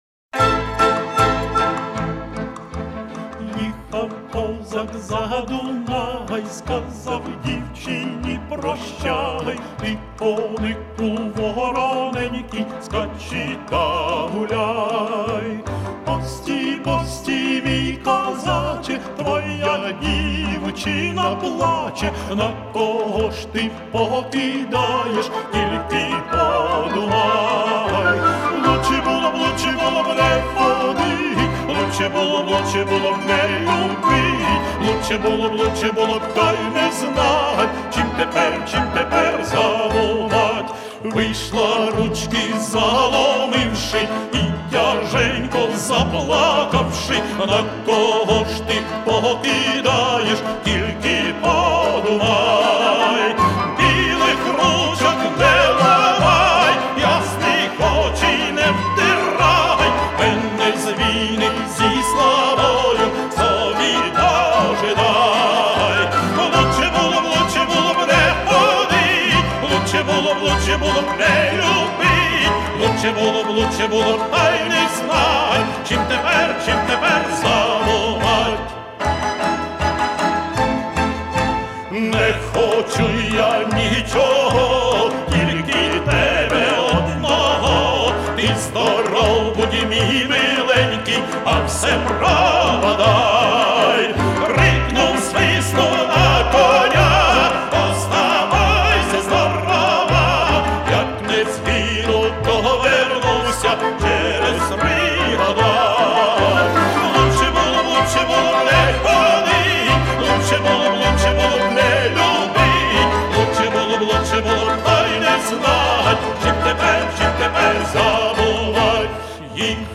Старинная песня.
Музыка: Слова та музика народні